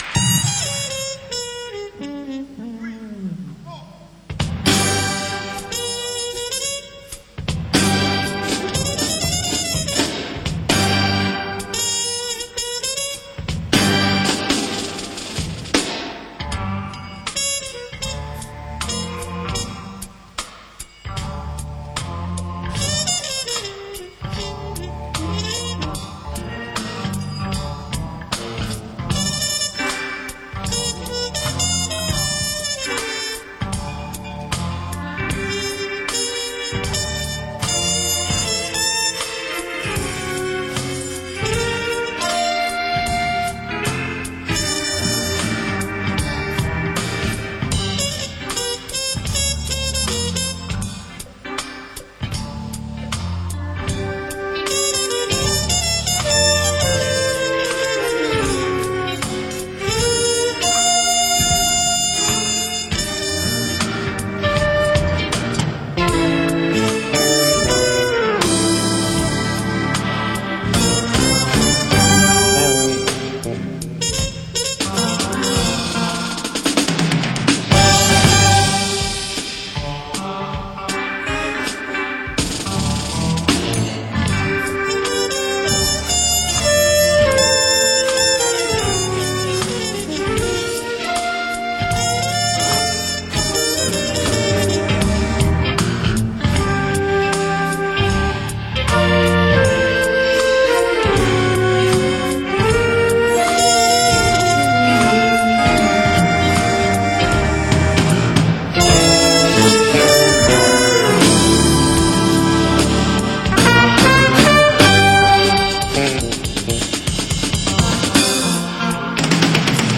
جاز، فانک، R&B